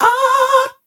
169 Aaaah (HQ)
Category 😂 Memes